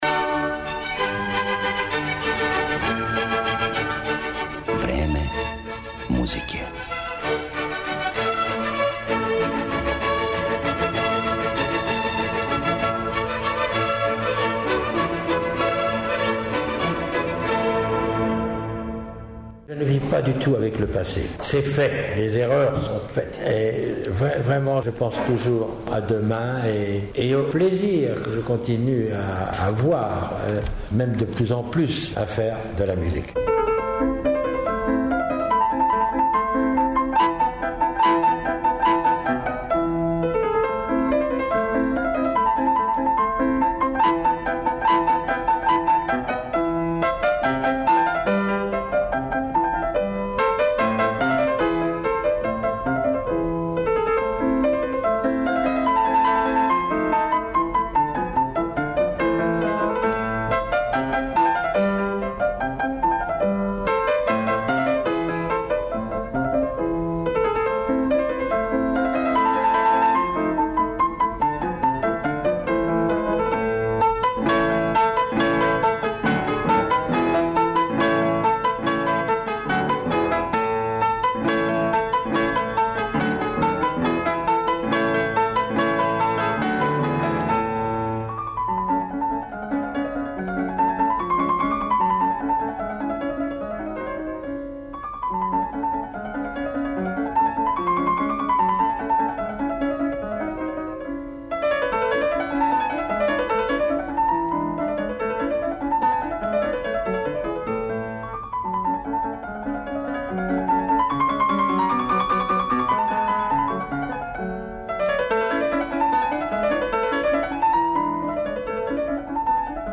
У емисији Време музике чућете његове интерпретације, као и изјаве које је дао на сусретима са новинарима.